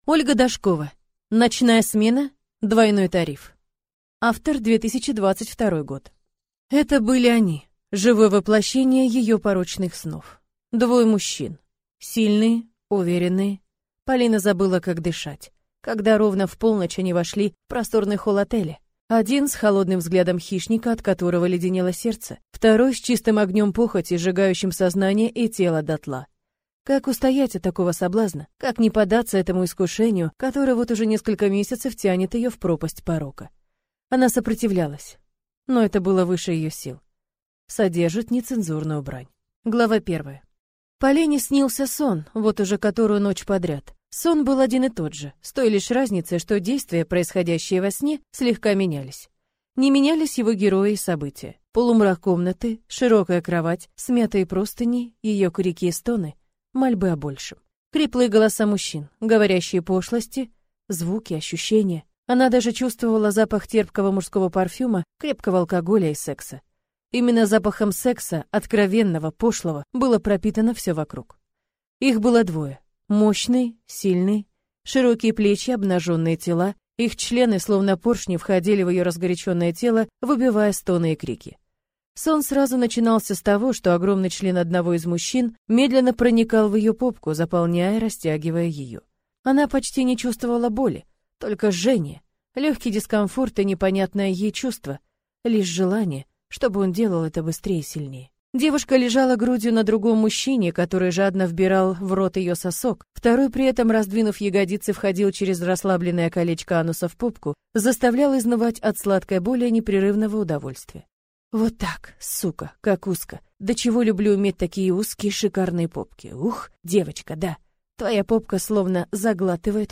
Аудиокнига Ночная смена. Двойной тариф | Библиотека аудиокниг